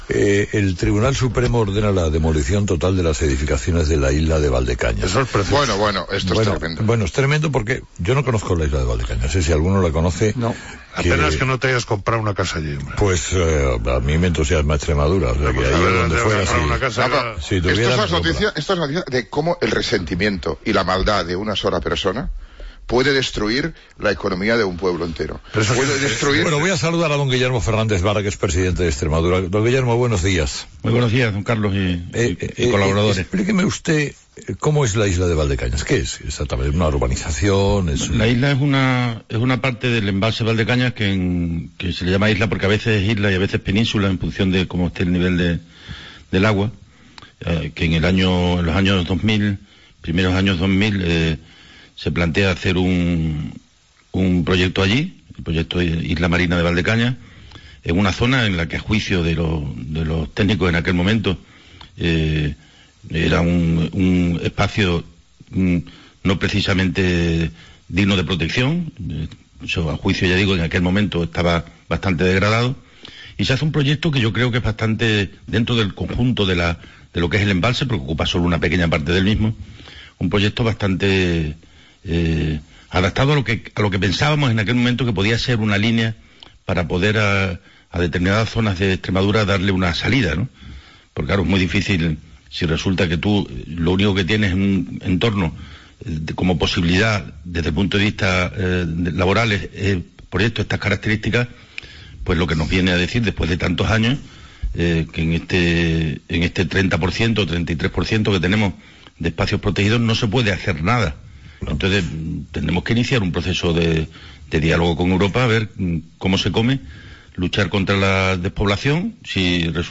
ENTREVISTA A VARA
Entrevista de Carlos Herrera a Guillermo Fernández Vara sobre Valdecañas